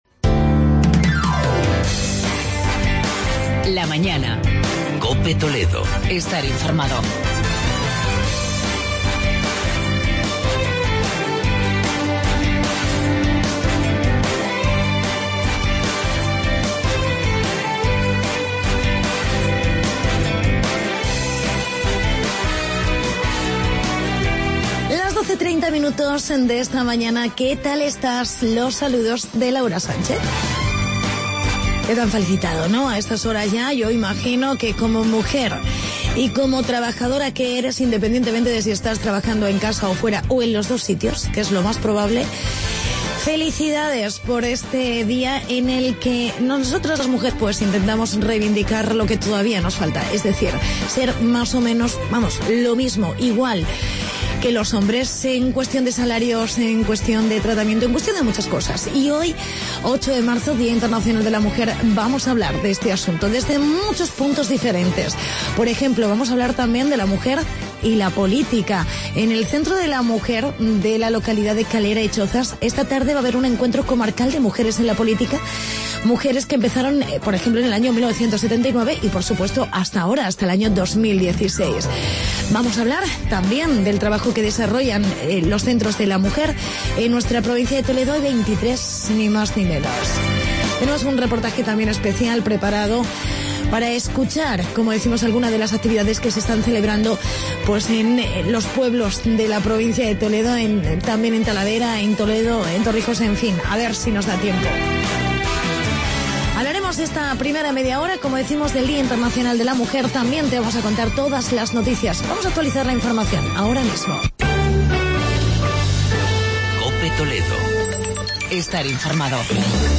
Reportaje sobre el Día Internacional de la Mujer